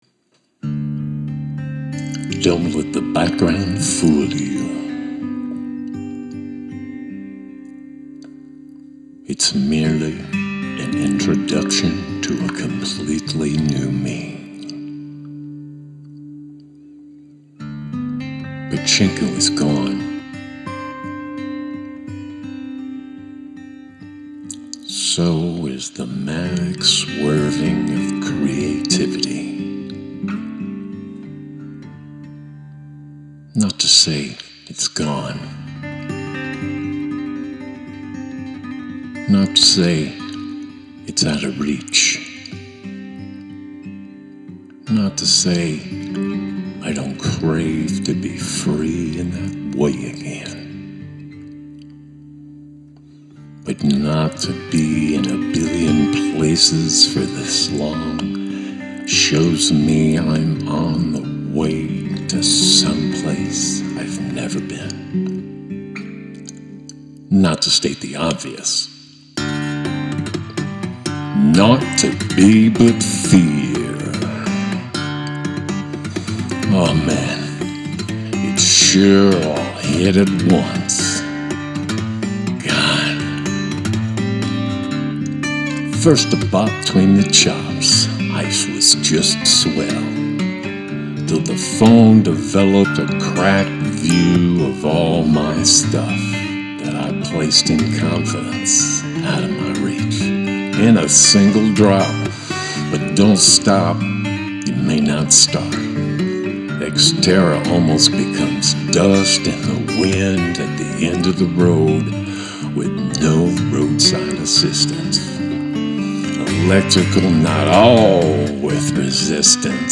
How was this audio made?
It is a good performance, although with mistakes, as I am doing it in one continuous take… a performance.